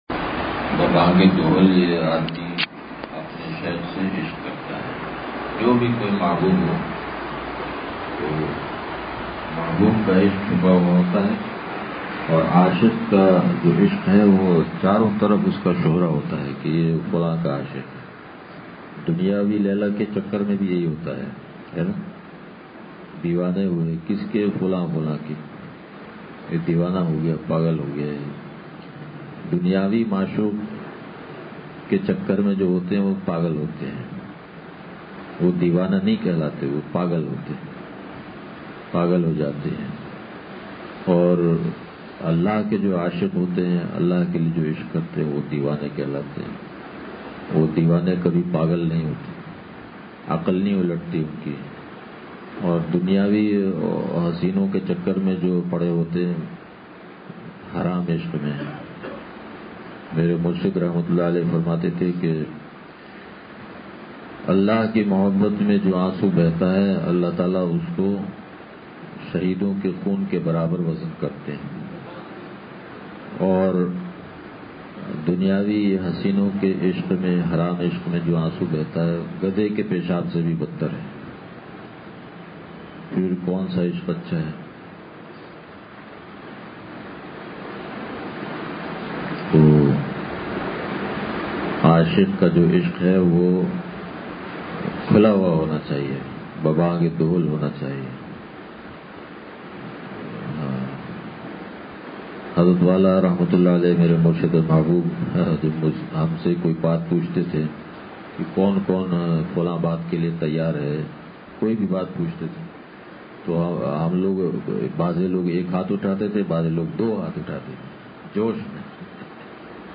بیان – لانڈھی